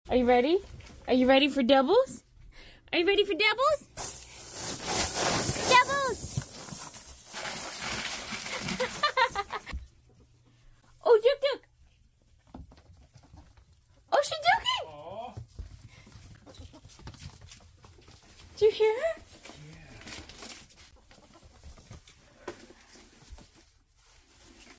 The happiest spund a ferret sound effects free download
The happiest spund a ferret can make!